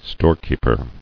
[store·keep·er]